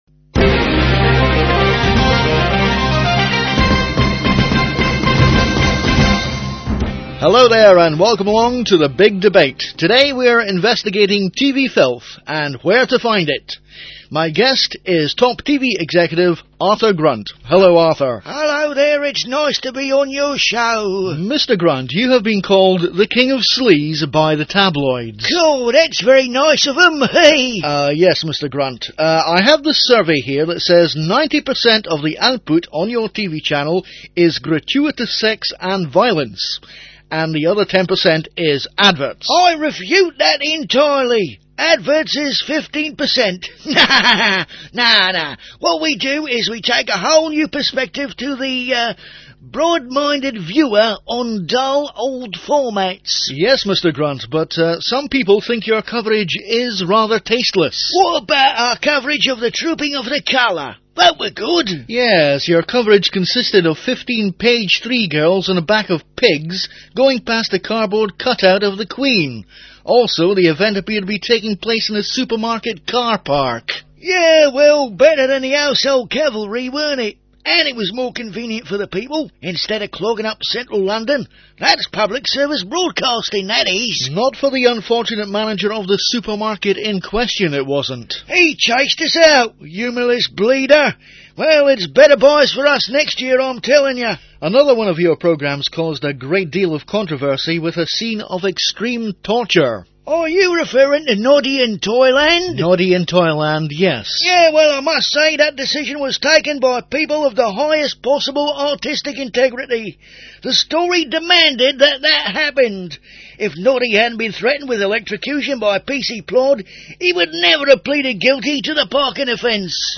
Comedy Sketches